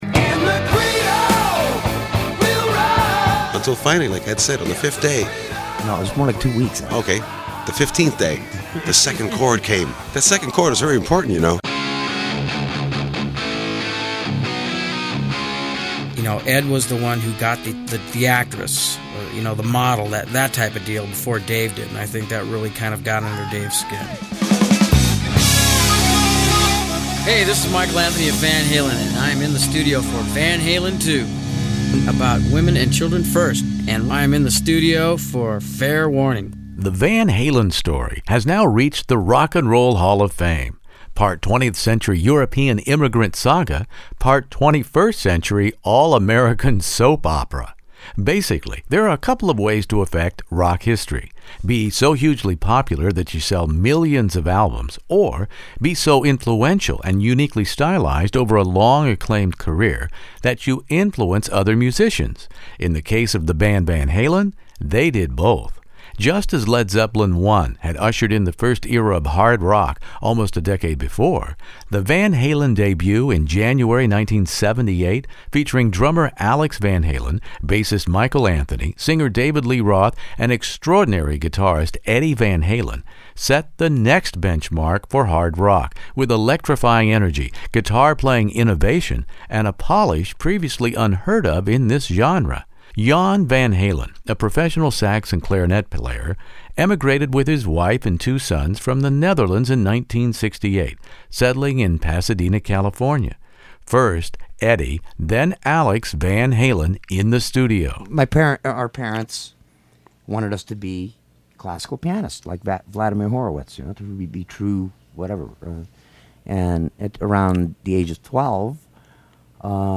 My guests in this classic rock interview are original band members singer David Lee Roth, bass player/harmony singer Michael Anthony, drummer Alex Van Halen, and fretmeister Eddie Van Halen reminding of us of a time at the dawn of the Eighties when Van Halen was establishing itself as America’s premiere hard rock band with songs “And the Cradle Will Rock” and “Everybody Wants Some!”. But the pace of almost constant touring, stopping only long enough to make another album, plus the band’s notorious hedonism, were starting to kill the buzz.